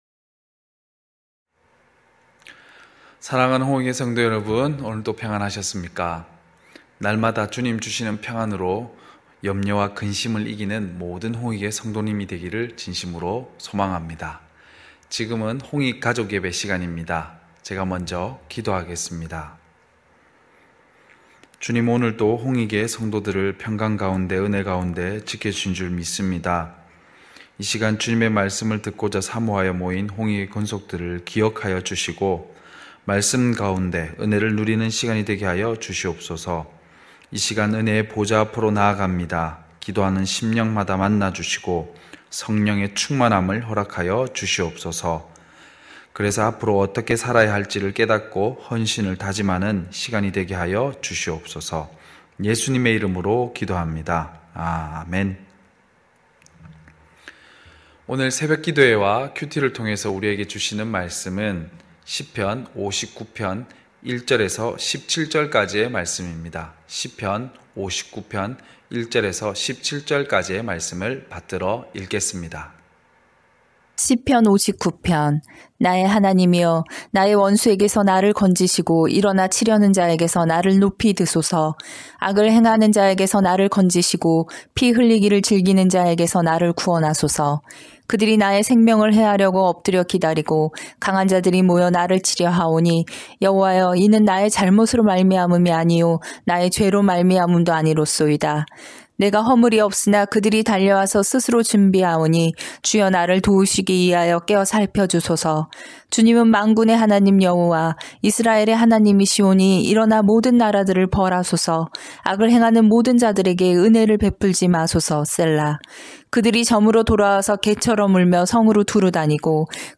9시홍익가족예배(3월9일).mp3